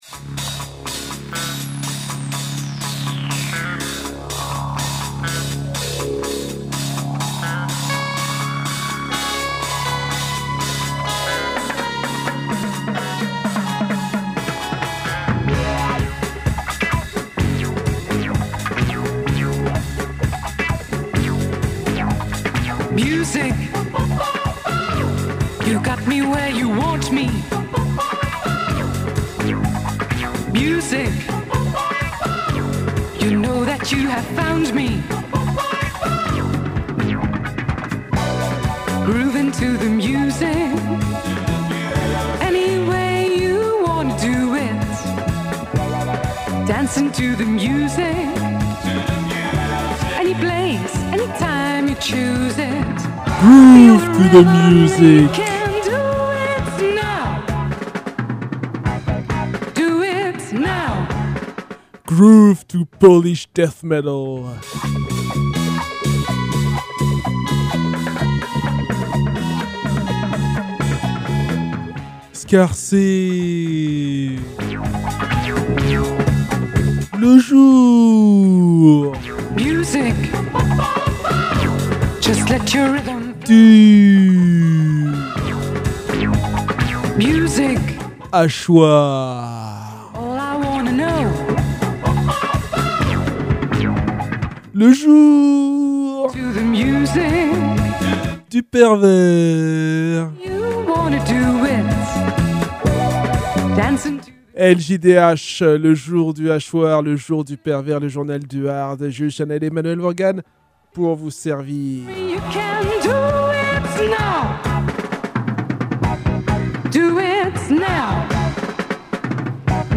deux combos death metal polonais déjà diffusés
le groupe black/death